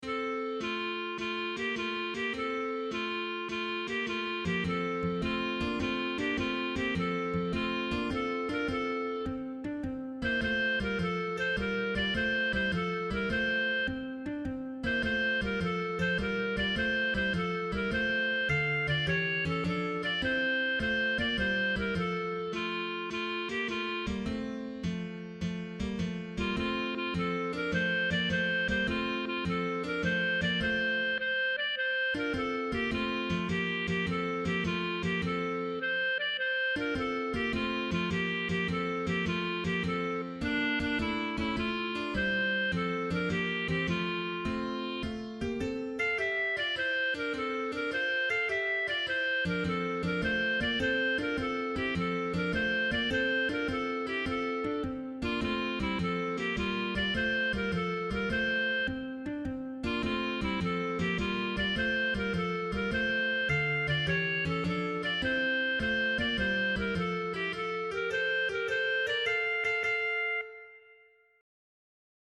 With two significant exceptions, Visit of the Wise Men and Der Gott aber der Hoffnung, these choral pieces ought to be well within the capability of a small church choir or singing group.
MP3 Christmas Arrangement: lively, simple SAB unaccompanied